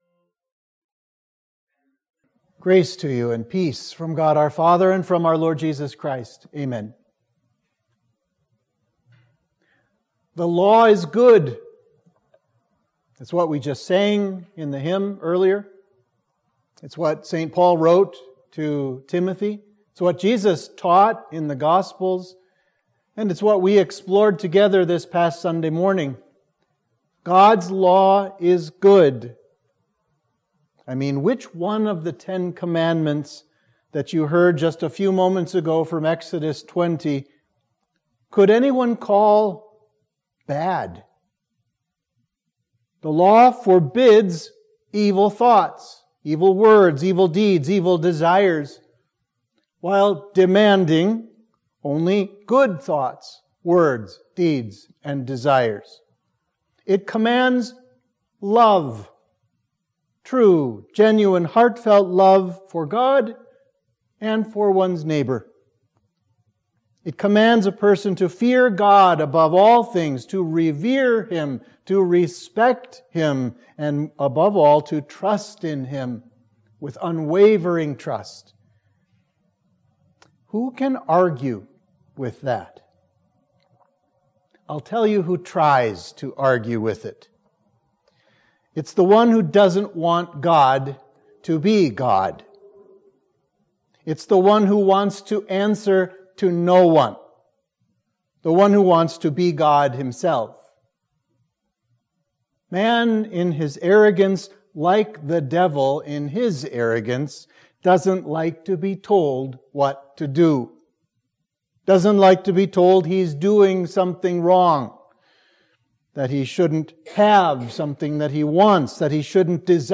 Sermon for Midweek of Trinity 6